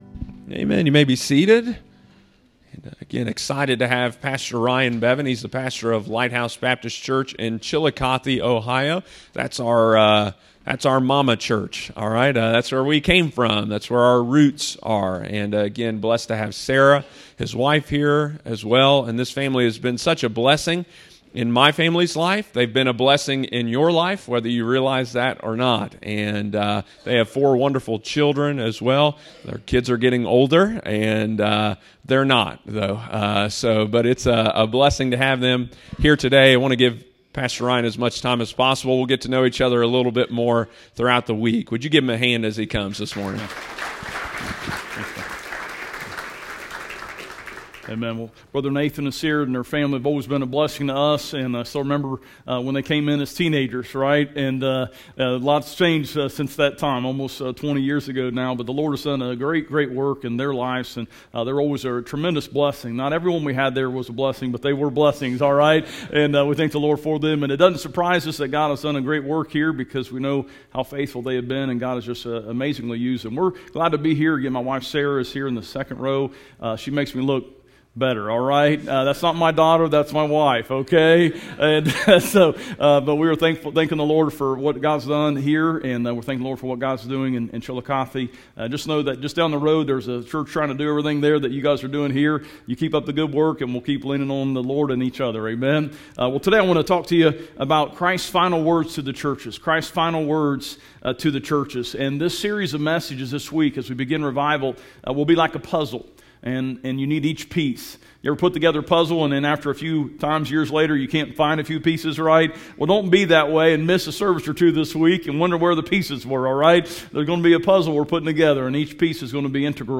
Series Spring Revival 2026